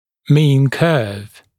[miːn kɜːv][ми:н кё:в]средняя кривая (напр. на графике)